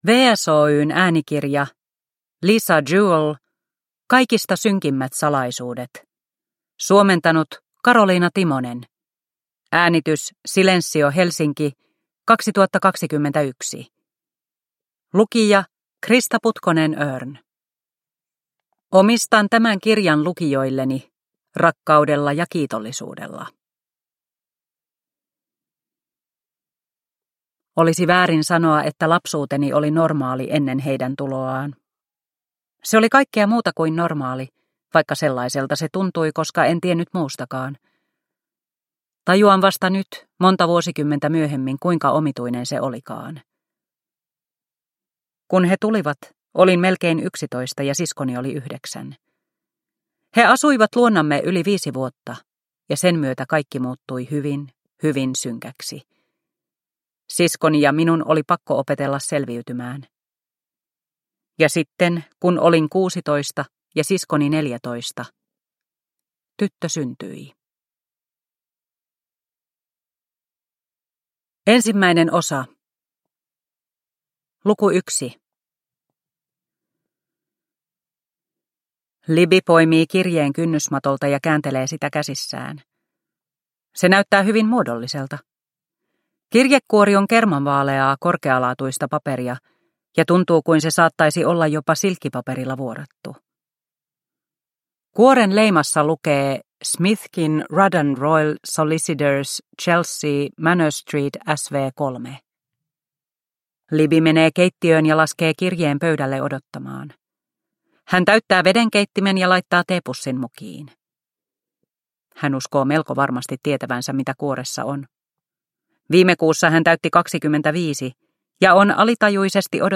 Kaikista synkimmät salaisuudet – Ljudbok – Laddas ner